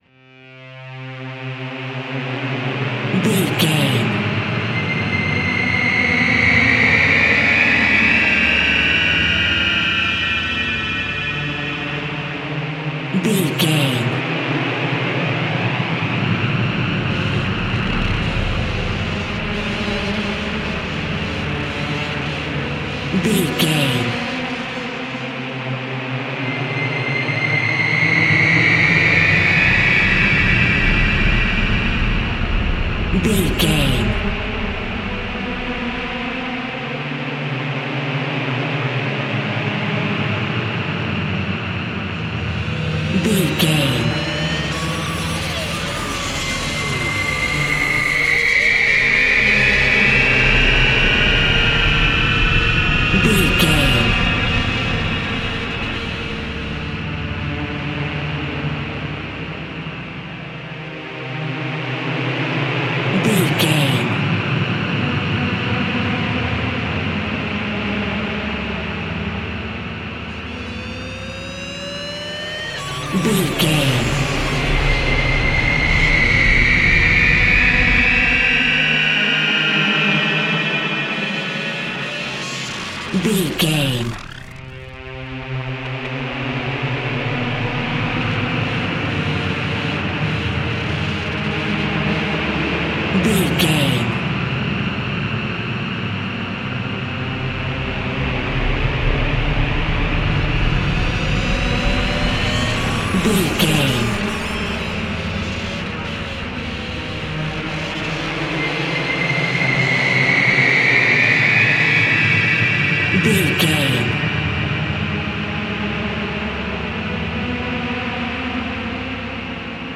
Atonal
ominous
suspense
eerie
synthesiser
wood wind